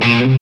GTR ROCK 0FR.wav